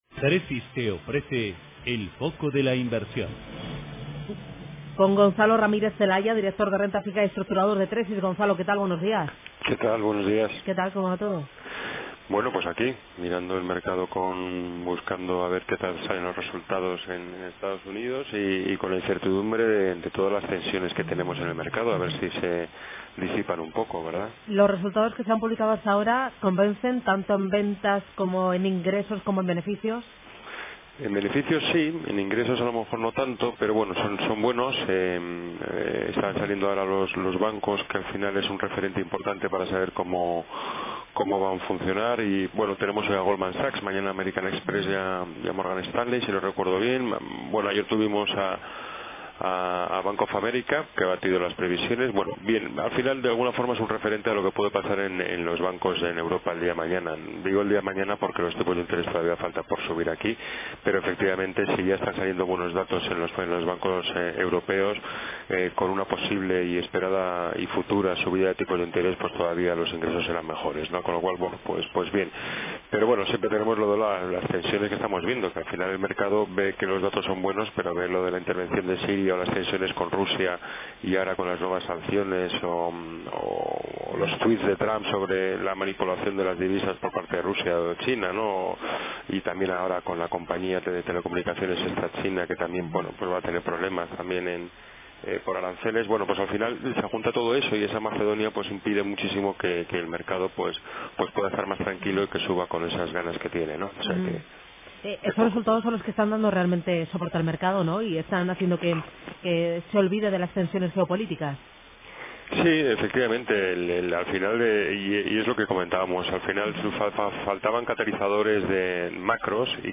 En la radio
En Radio Intereconomía todas las mañanas nuestros expertos analizan la actualidad de los mercados.